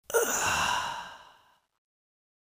last-breath-sounds